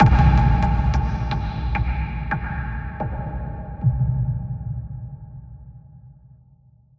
Processed Hits 14.wav